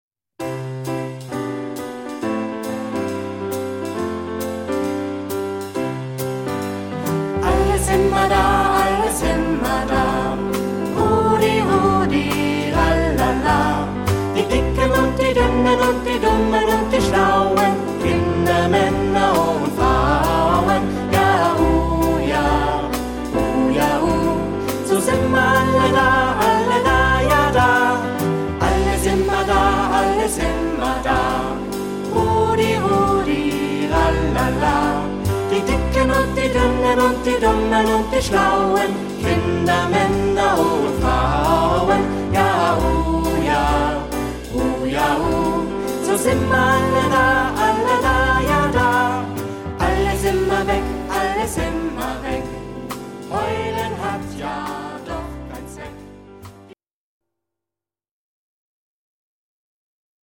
Ad libitum (3 Ad libitum Stimmen).
Kanon. Choraljazz.
Charakter des Stückes: jazzy ; fröhlich ; rhythmisch
Tonart(en): C-Dur